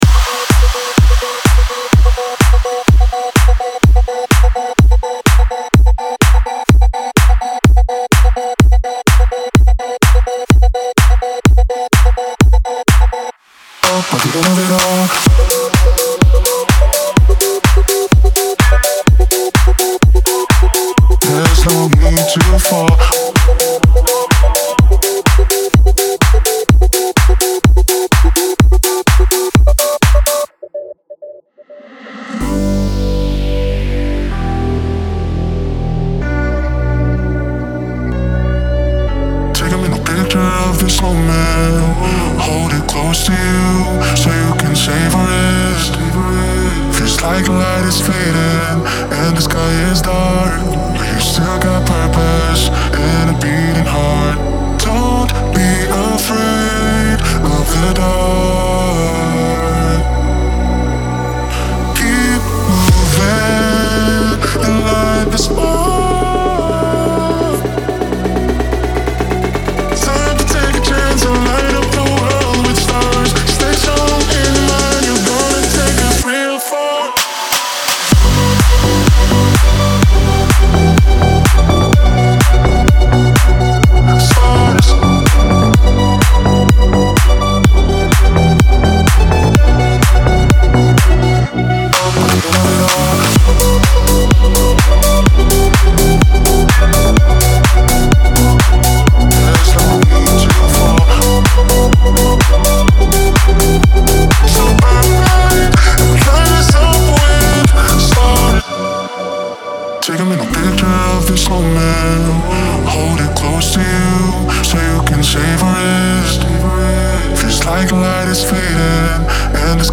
И непрерывный танец, наполненный чувствами.
легкий и теплый